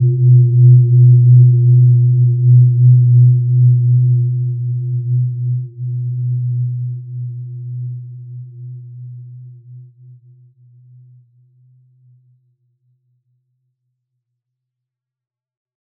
Gentle-Metallic-3-B2-p.wav